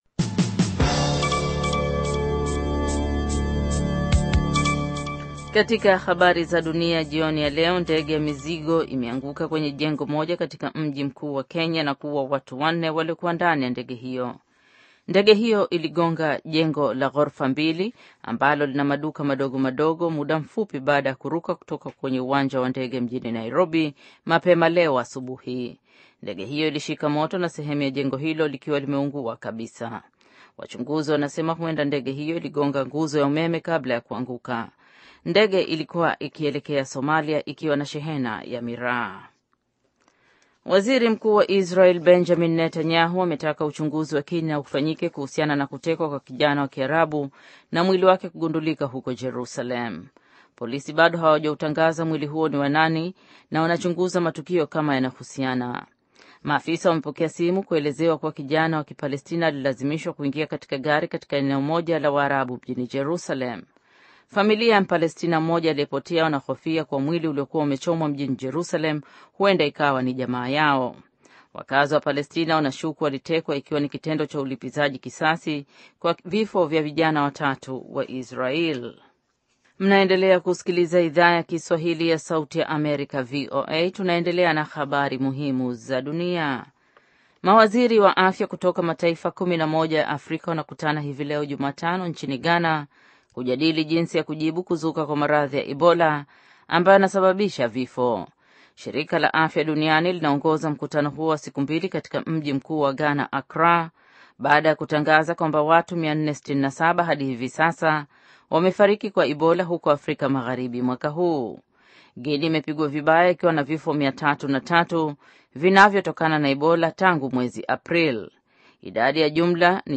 Taarifa ya Habari VOA Swahili - 5:52